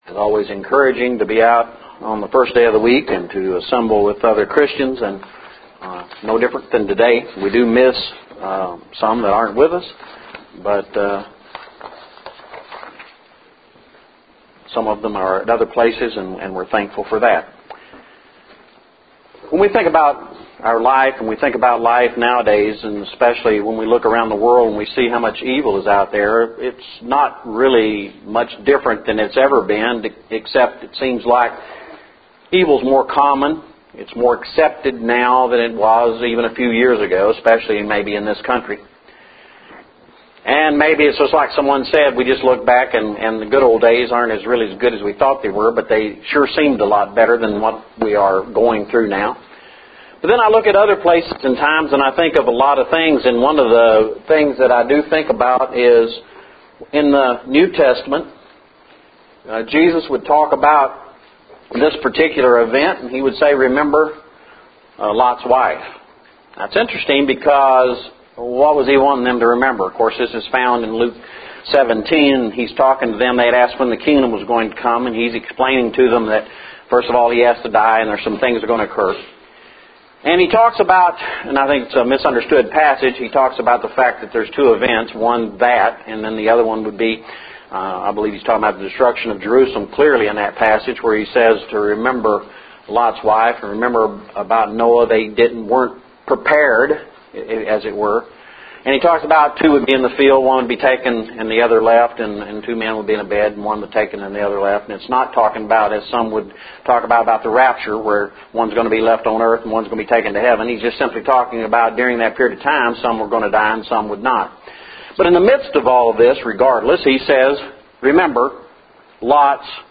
lessons for today were on the wives of Lot and Nabal. Two men and two women in very different situations.